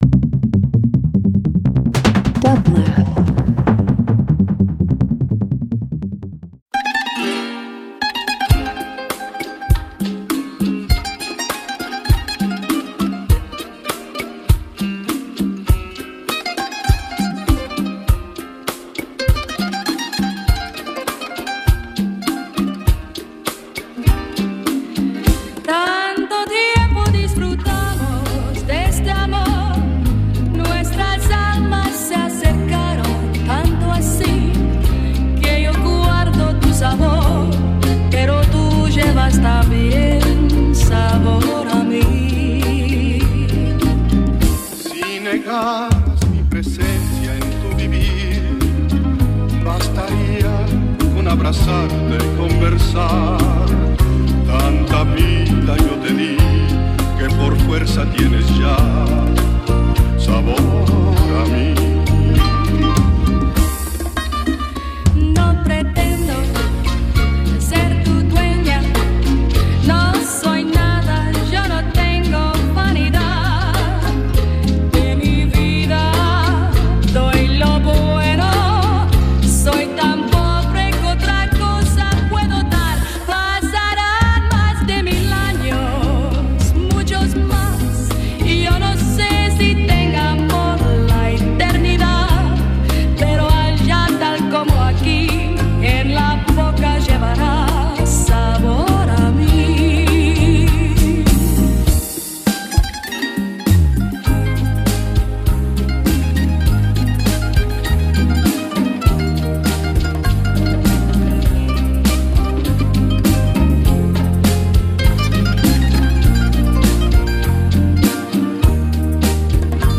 Dance International Latin